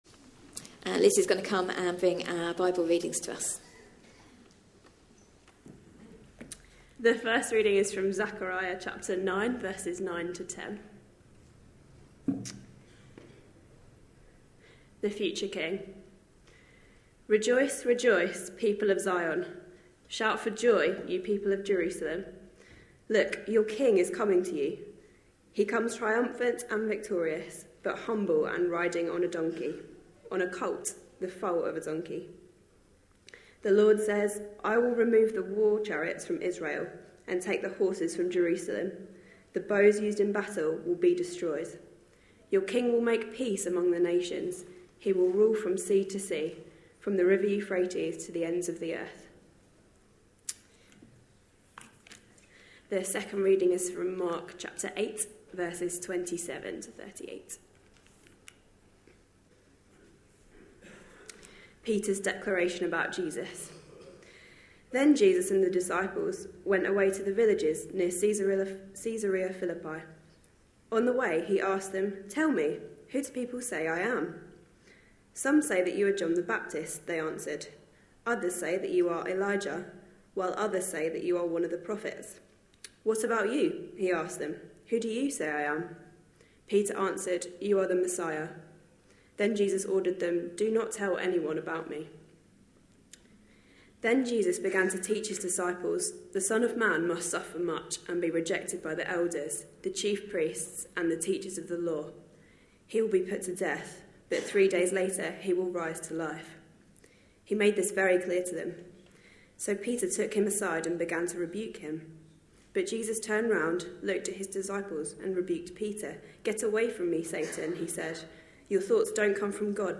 A sermon preached on 25th March, 2018.